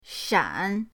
shan3.mp3